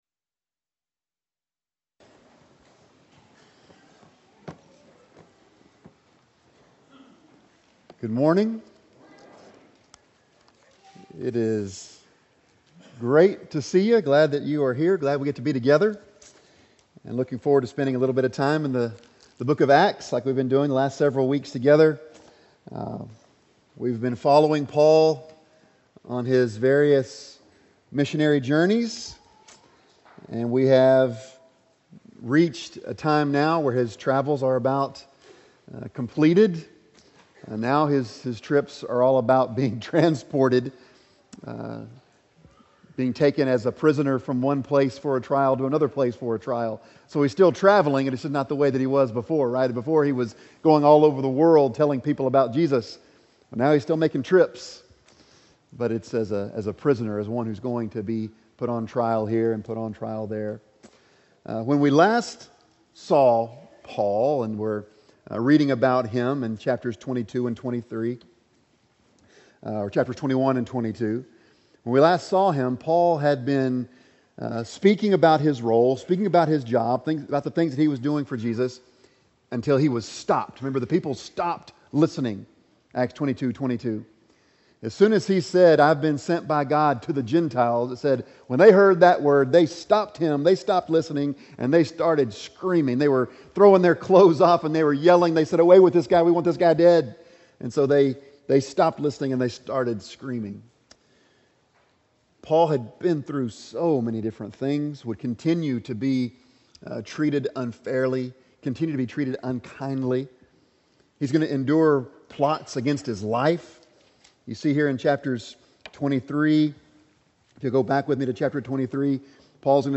Acts 24:24-25 Service Type: Sunday Morning Bible Text